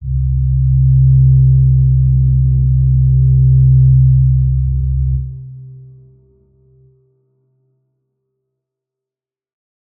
G_Crystal-C3-pp.wav